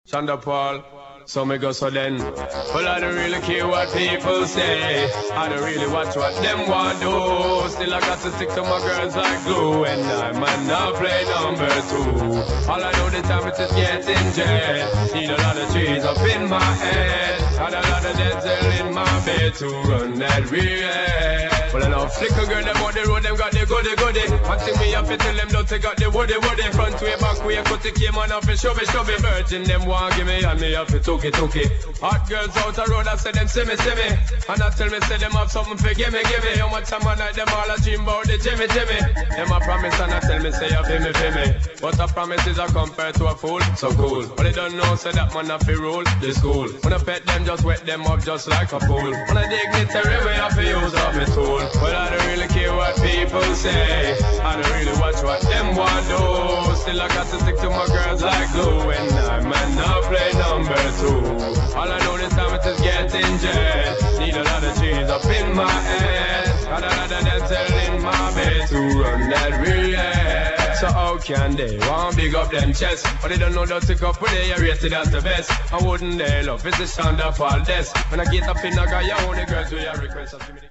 [ REGGAE / DANCEHALL / RAGGA HIP HOP ]